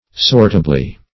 sortably - definition of sortably - synonyms, pronunciation, spelling from Free Dictionary Search Result for " sortably" : The Collaborative International Dictionary of English v.0.48: Sortably \Sort"a*bly\, adv.